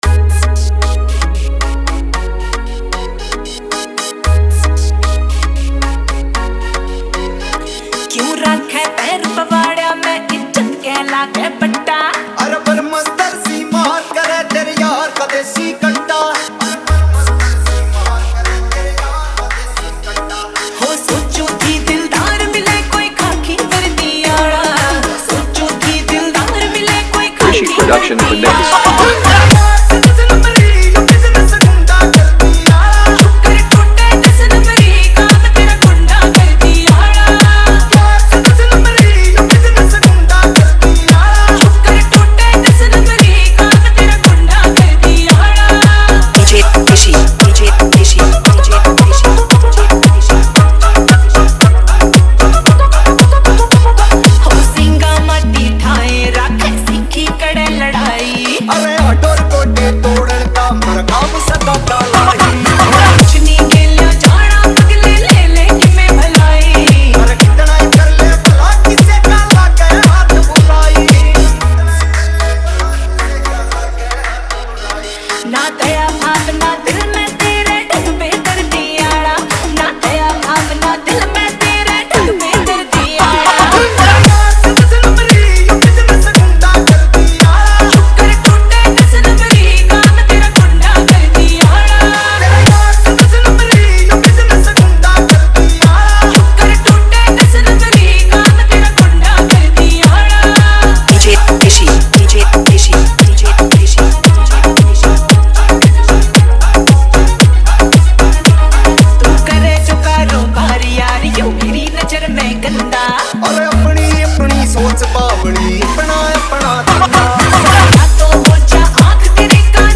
dj Song